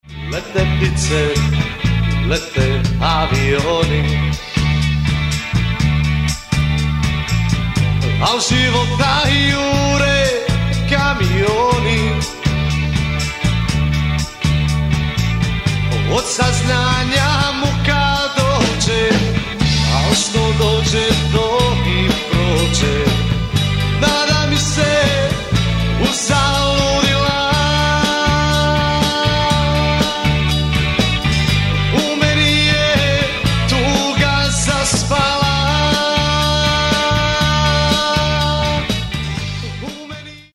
Mixano u "Profile Studios" Vancouver BC
u "Slanina Studios" Vancouver BC...